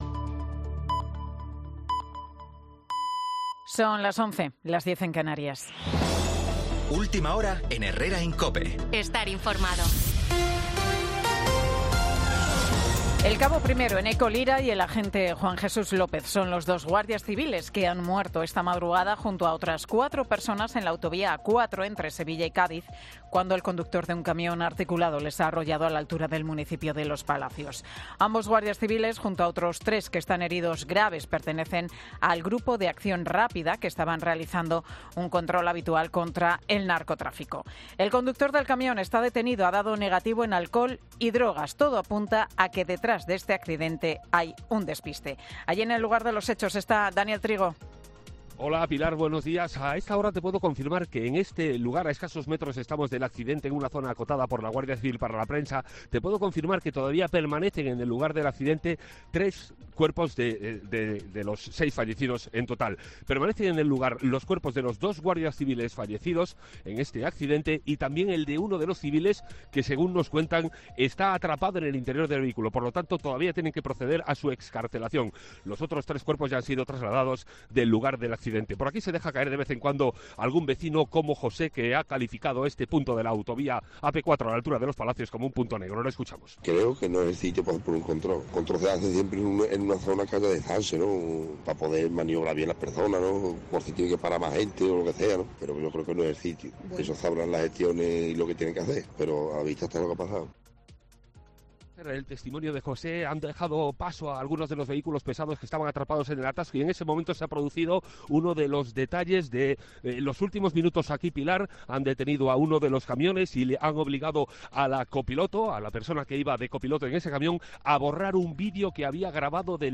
Boletín de Noticias de COPE del 19 de marzo del 2024 a las 11 horas